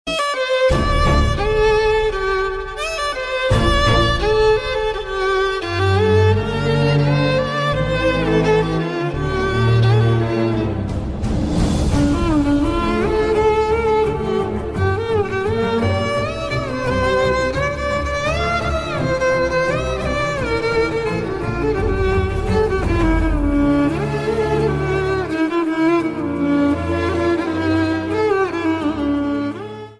Instrumentalne